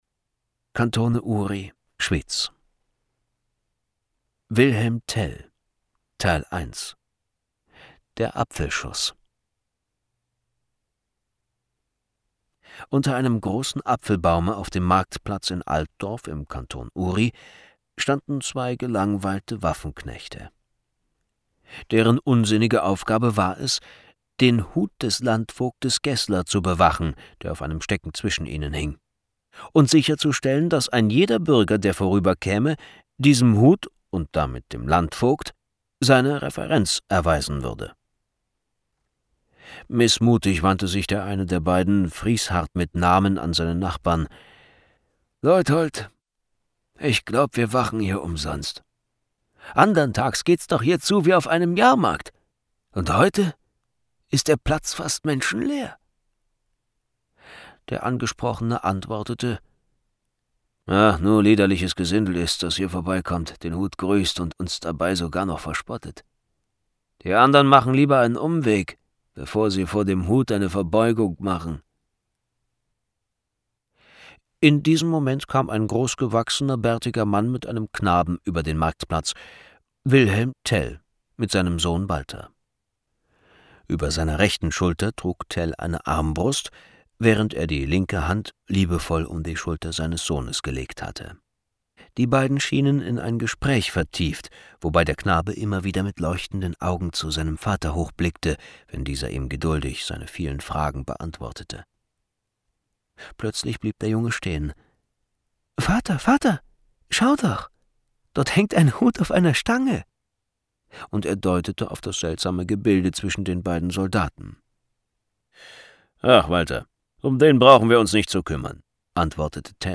Hörbuch
mit seiner warmen und wandelbaren Stimme